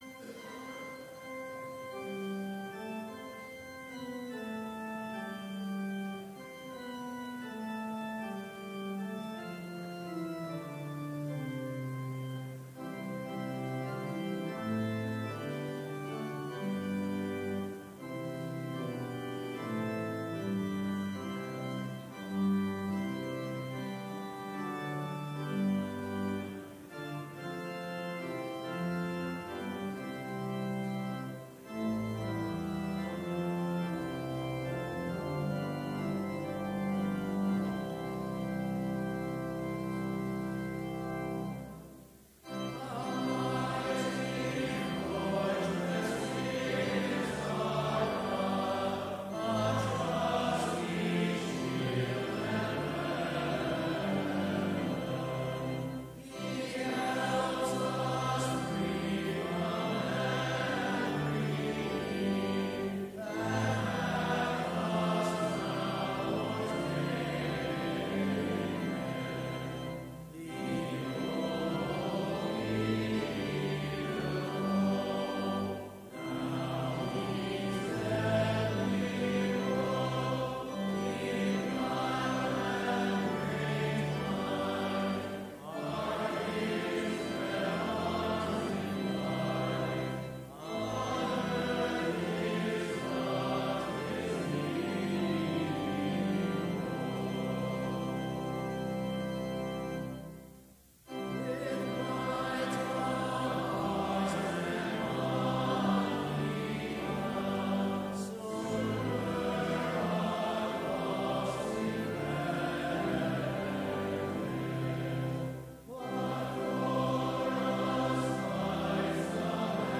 Complete service audio for Chapel - March 27, 2019